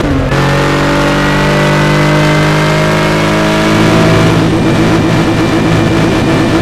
fourth_cruise.wav